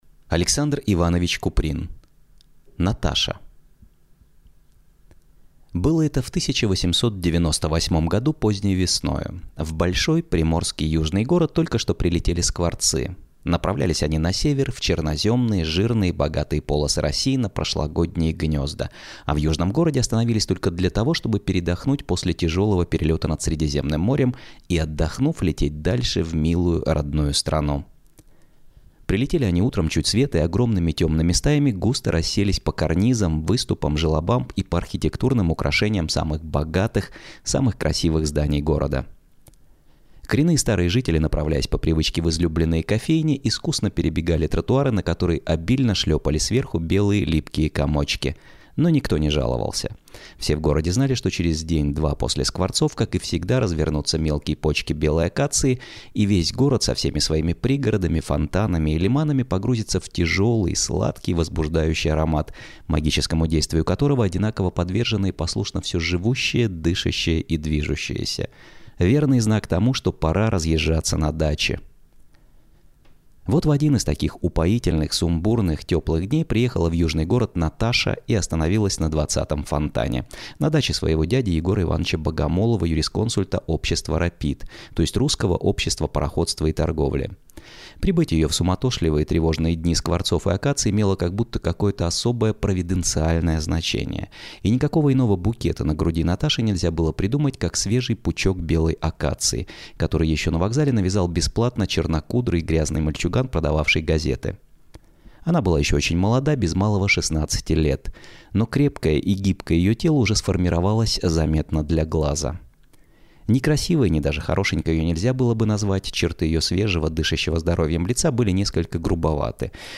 Аудиокнига Наташа | Библиотека аудиокниг